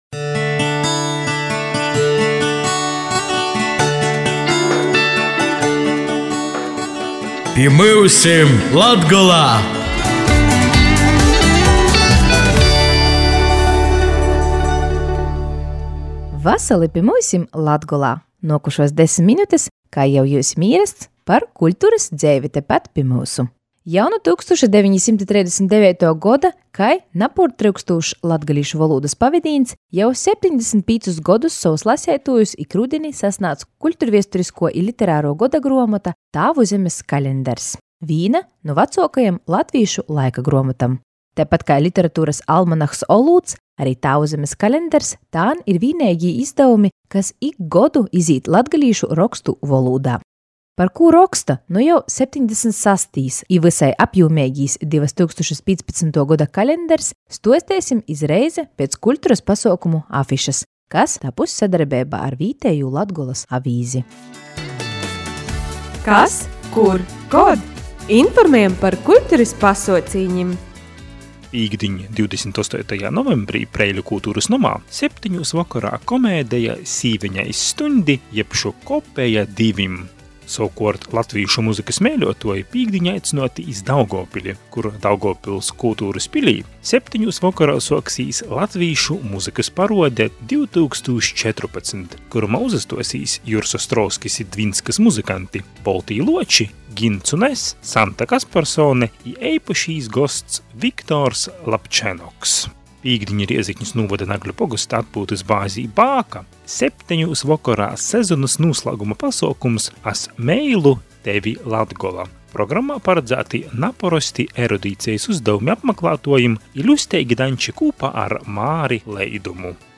Raidejums “Pi myusim Latgolā” skaņ radejā “Ef-Ei” (FM 91,4 Rēzekne) i “Divu krastu radio” (FM 96,9 Jākubpiļs, FM 90,1 Daugovpiļs):